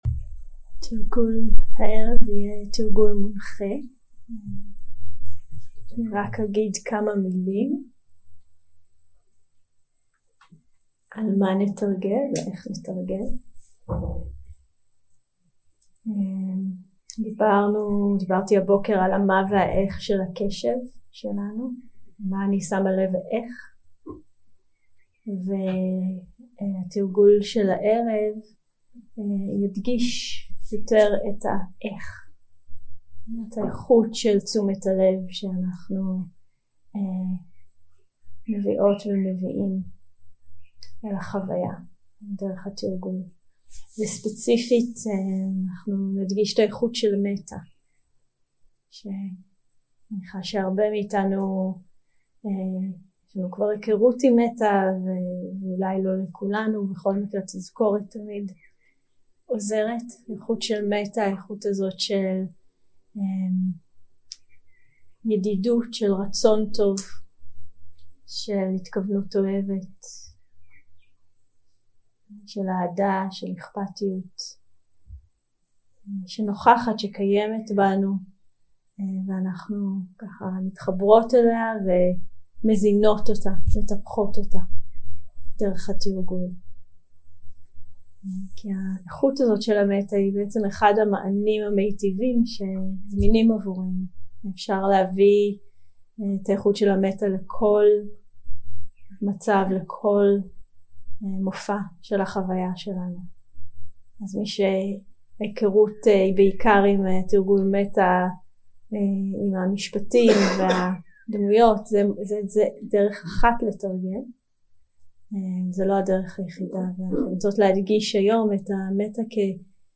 יום 2 – הקלטה 4 – ערב – מדיטציה מונחית – מטא קורנת
Dharma type: Guided meditation שפת ההקלטה